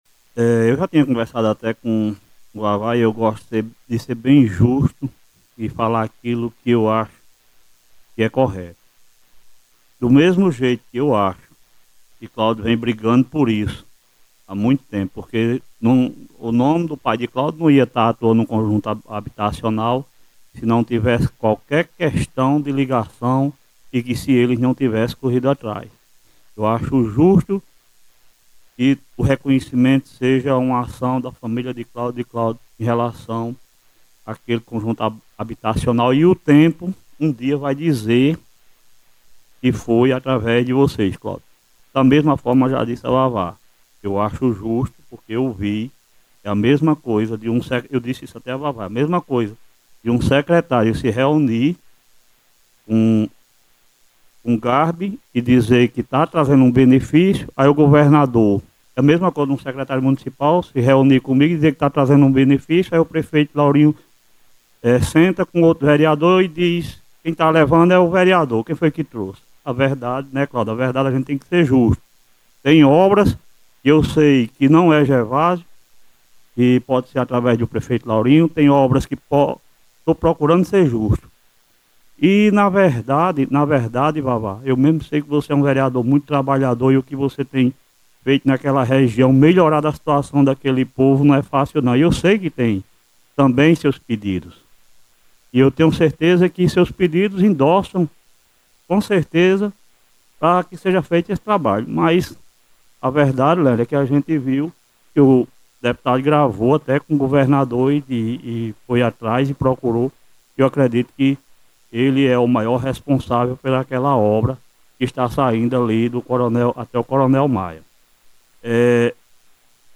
Durante sessão na Câmara Municipal de Catolé do Rocha na tarde desta segunda-feira, dia 23 de fevereiro, o presidente do Legislativo, Cleverlando Barreto, saiu em defesa do reconhecimento justo às lideranças que, segundo ele, tiveram participação direta na conquista de importantes obras para o município.